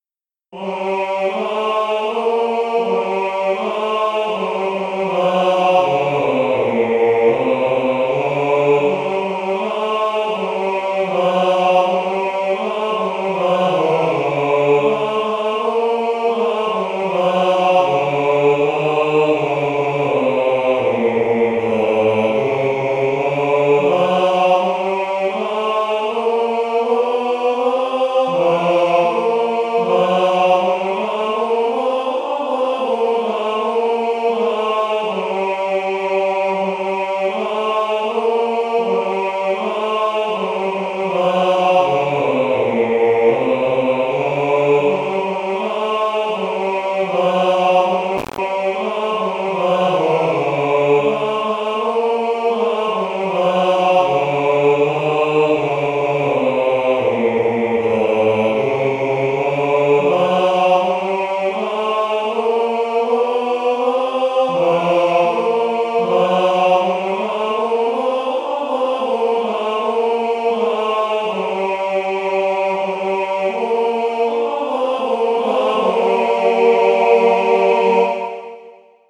Version mit Synthesizer-Stimmen (sopran/alt/männer/tutti)